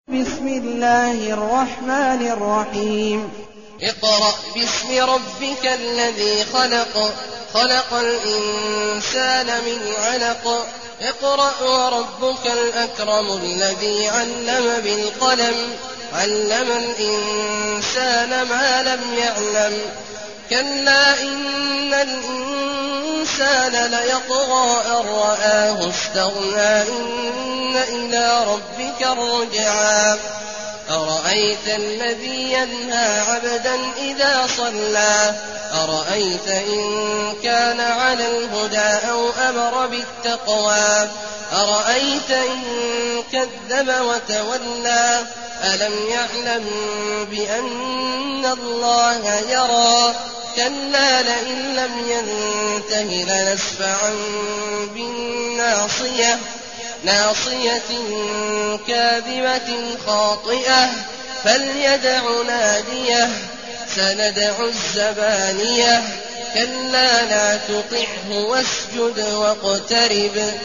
المكان: المسجد الحرام الشيخ: عبد الله عواد الجهني عبد الله عواد الجهني العلق